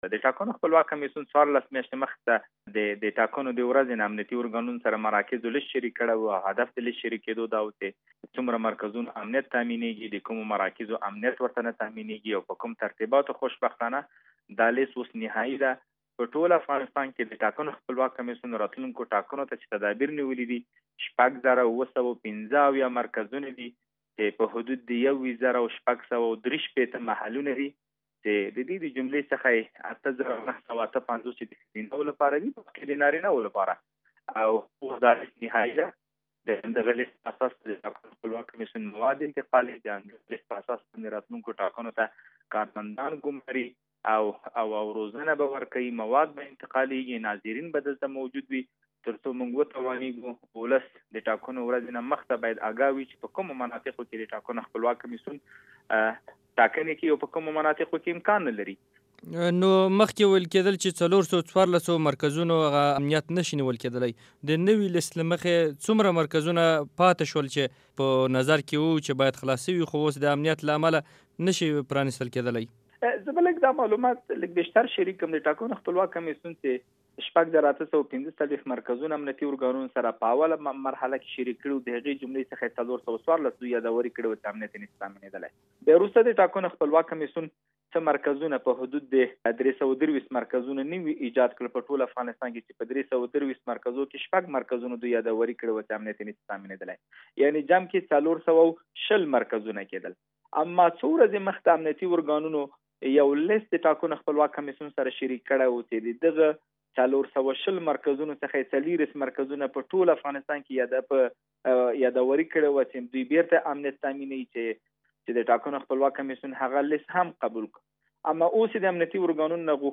له ضيا الحق امرخېل سره مرکه